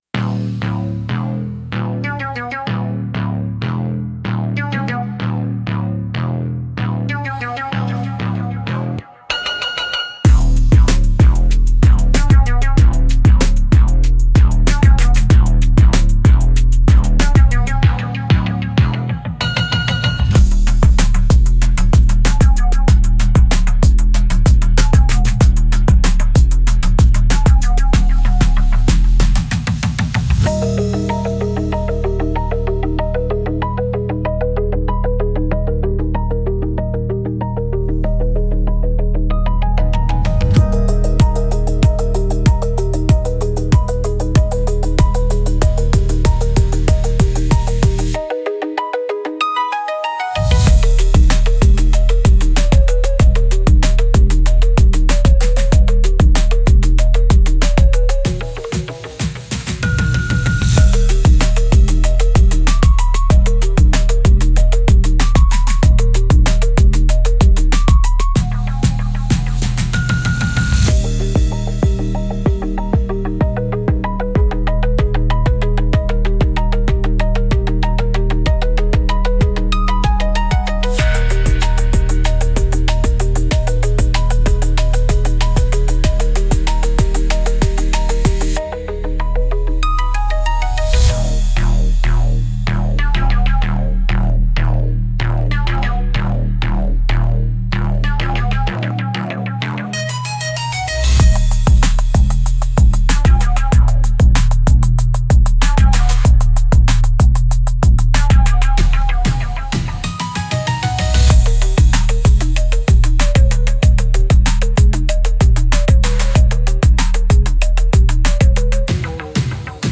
Instrumental - Thrum- 2.44 mins